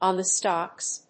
アクセントon the stócks